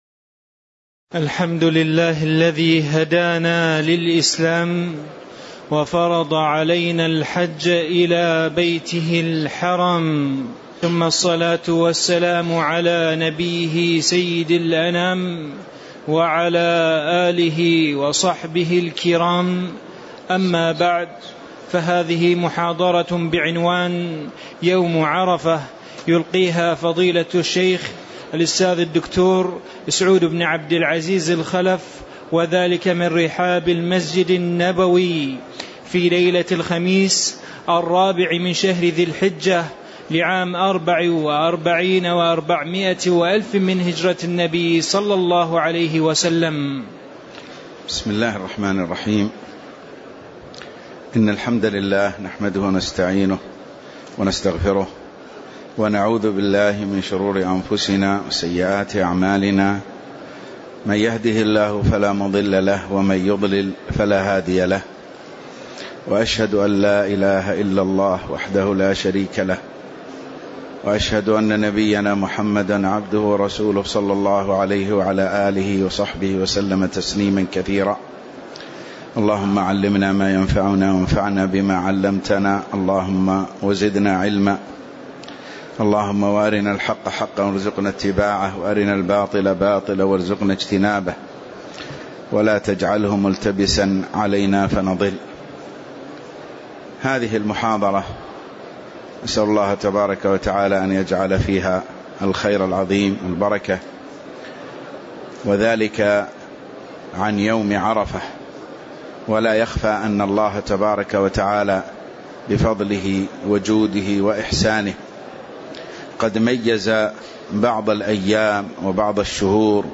تاريخ النشر ٣ ذو الحجة ١٤٤٤ هـ المكان: المسجد النبوي الشيخ